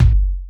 Kick_04.wav